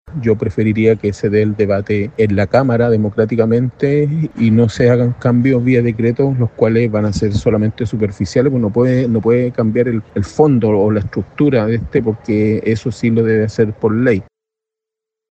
En este sentido, el diputado Boris Barrera (PC) afirmó que, desde su perspectiva, estos cambios en el sistema deberían ser a través de la Cámara Baja y no vía decreto, como se presume lo hará el gobierno.